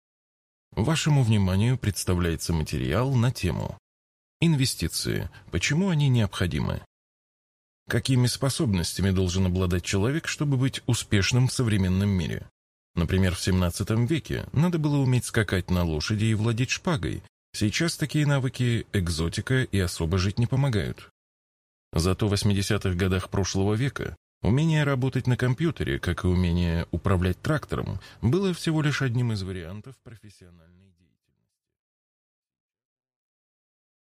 Аудиокнига Заметки в инвестировании. Книга об инвестициях и управлении капиталом.